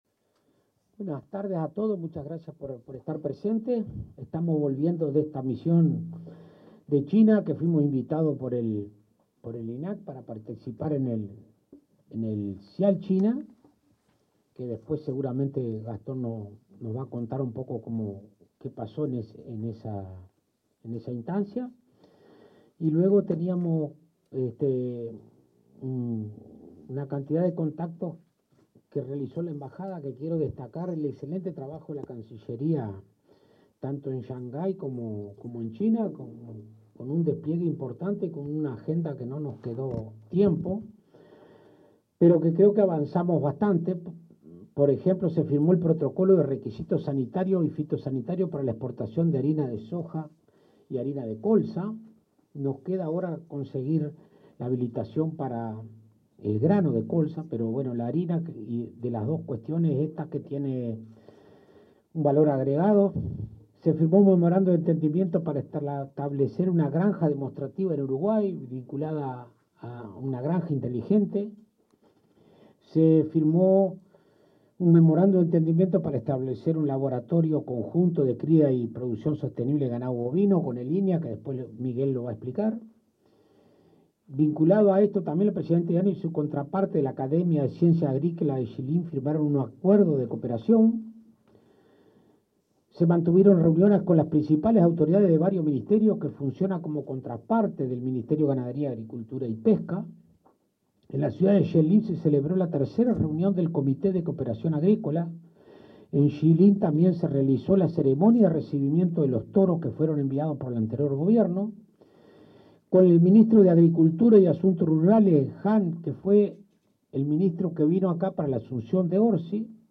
Conferencia de prensa en el MGAP
Conferencia de prensa en el MGAP 27/05/2025 Compartir Facebook X Copiar enlace WhatsApp LinkedIn El titular del Ministerio de Ganadería, Agricultura y Pesca (MGAP), Alfredo Fratti; el presidente del Instituto Nacional de Carnes, Gastón Scayola, y su par del Instituto Nacional de Investigación Agropecuaria, Miguel Sierra, informaron a la prensa acerca de una misión oficial en la República Popular China.